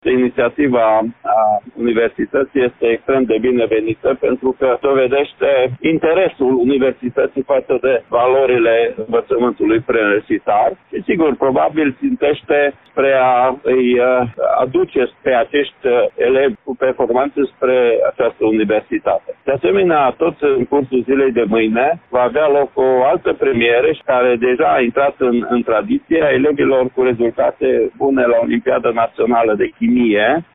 Inspectorul școlar general Ștefan Someșan.